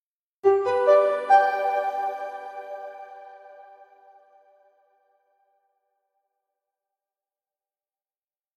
Современный звук подключения к интернету